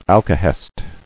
[al·ka·hest]